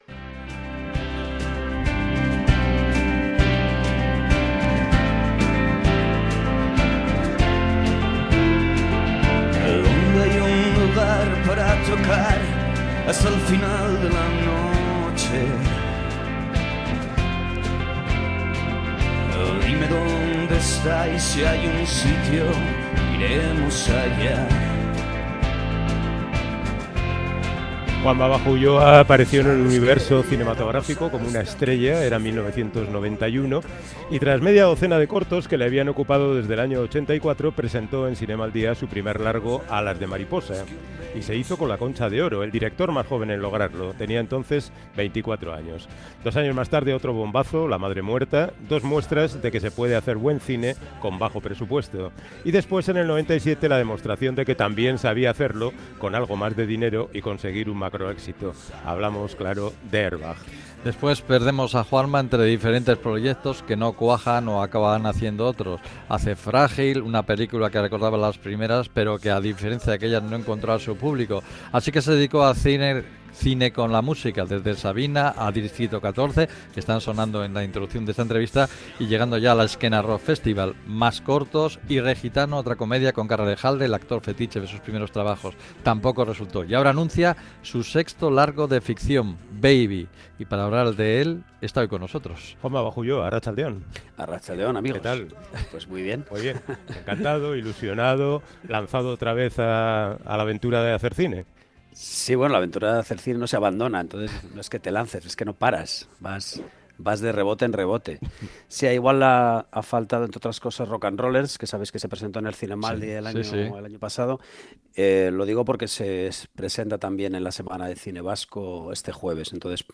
Audio: Charlamos desde la librería La Afición Literaria de Gasteiz con el director de cine Juanma Bajo Ulloa que nos habla del proyecto de su próxima película, Baby